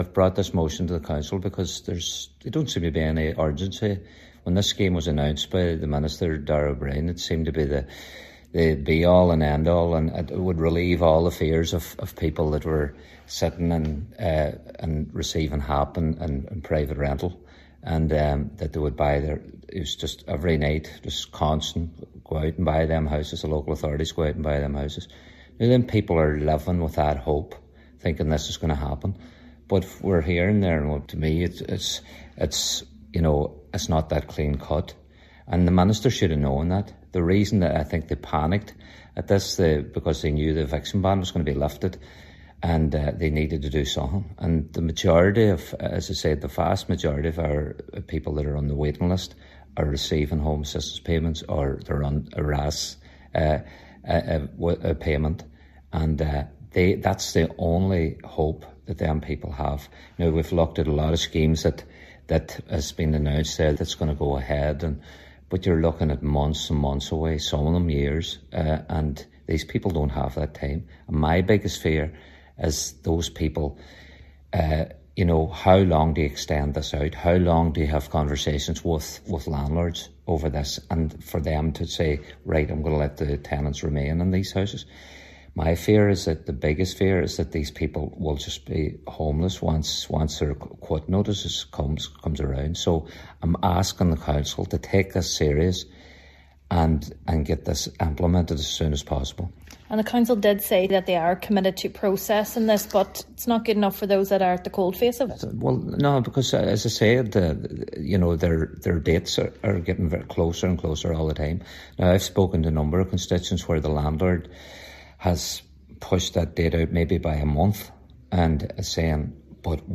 However, Councillor Jordan says people are at their wits end: